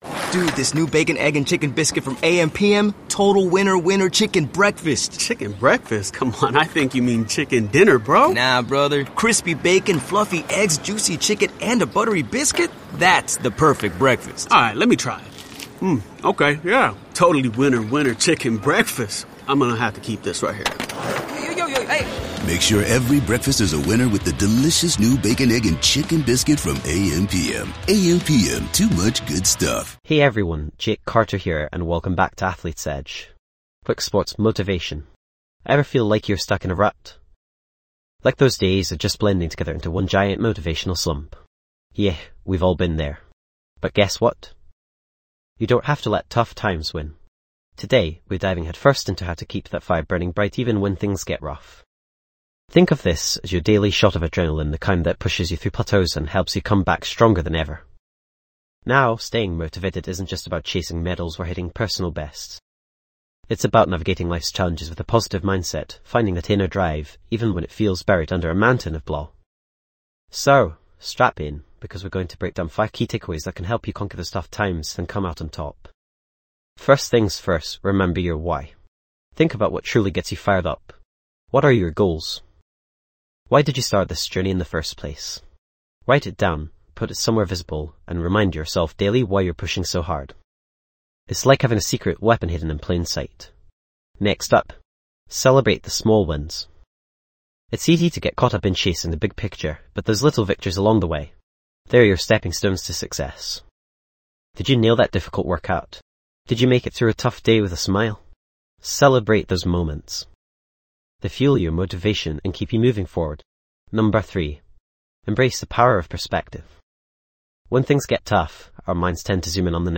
Podcast Category:. Sports & Recreation Motivational Talks
This podcast is created with the help of advanced AI to deliver thoughtful affirmations and positive messages just for you.